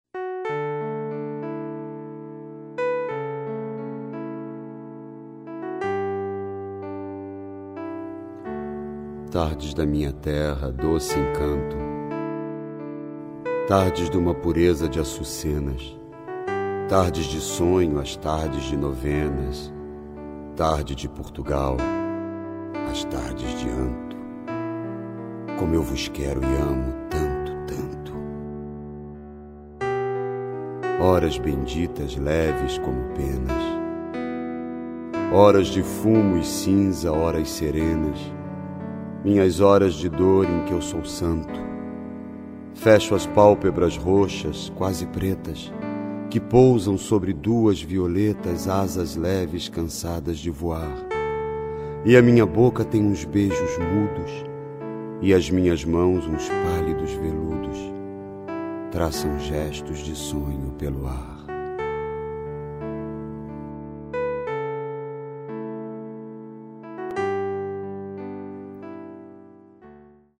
19 - Languidez (Florbela Espanca - declamação: Miguel Falabella)